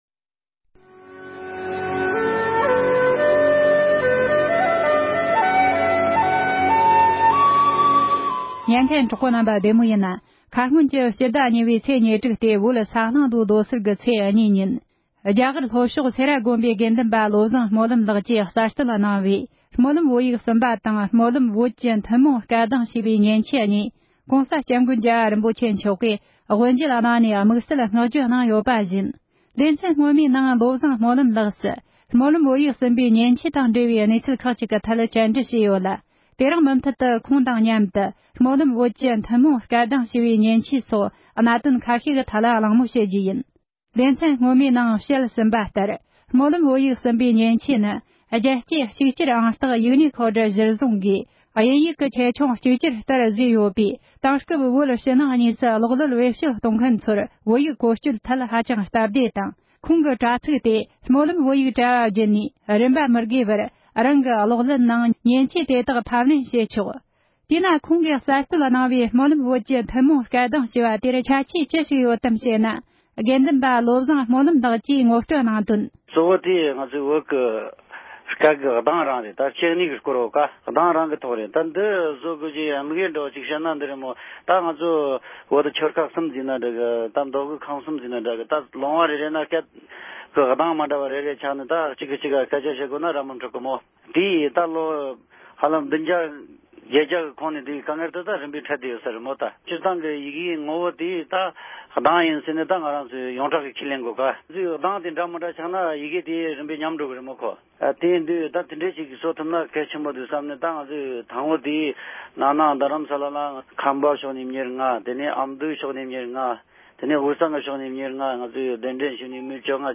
སྨོན་ལམ་བོད་ཡིག་གསུམ་པ་དང་སྨོན་ལམ་བོད་ཀྱི་ཐུན་མོང་སྐད་གདངས་སྐོར་ལ་མཉེན་ཆས་གསར་གཏོད་གནང་དང་ལྷན་དུ་གླེང་མོལ་ལེའུ་གཉིས་པ།